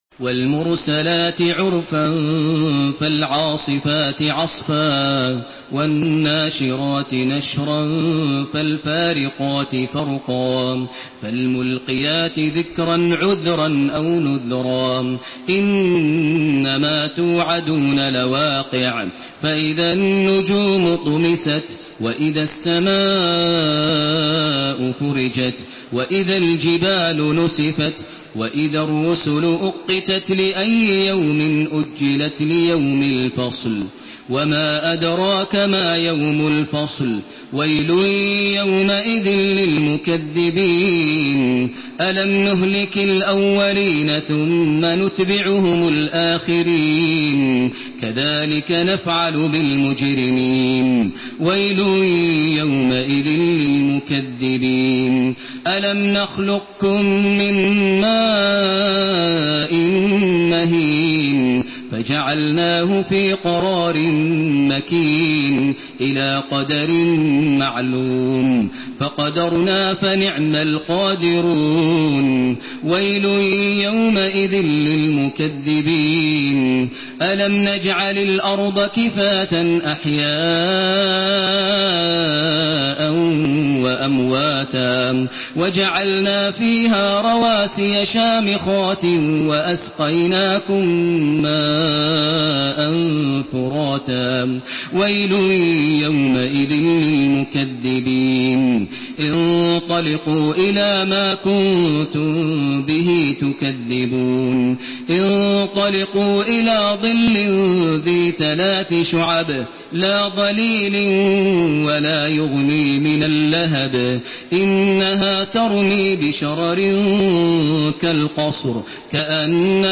Surah আল-মুরসালাত MP3 by Makkah Taraweeh 1432 in Hafs An Asim narration.
Murattal Hafs An Asim